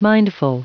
Prononciation du mot mindful en anglais (fichier audio)
Prononciation du mot : mindful